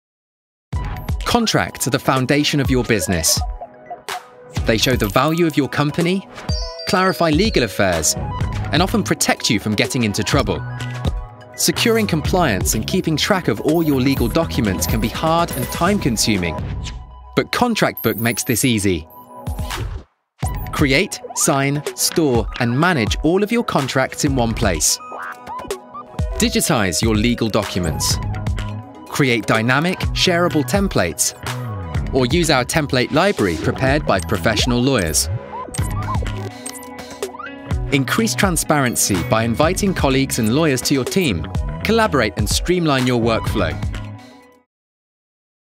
English (British)
Commercial, Natural, Cool, Accessible, Friendly
Audio guide